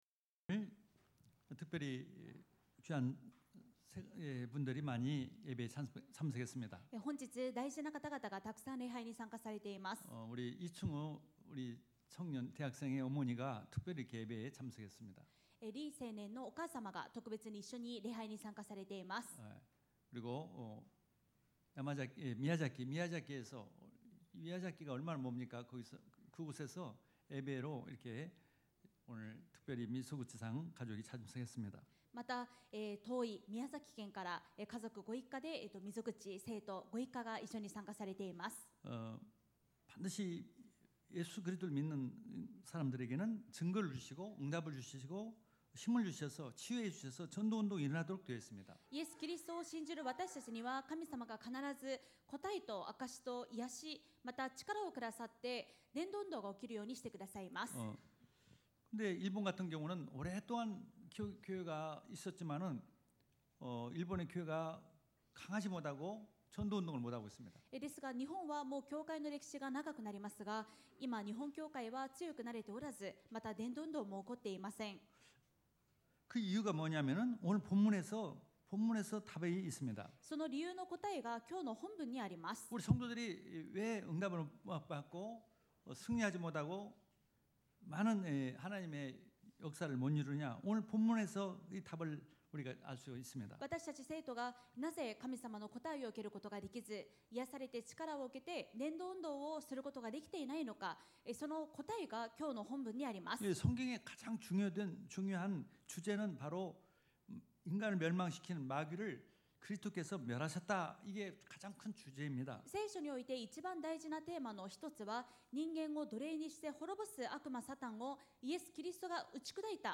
主日3部メッセージ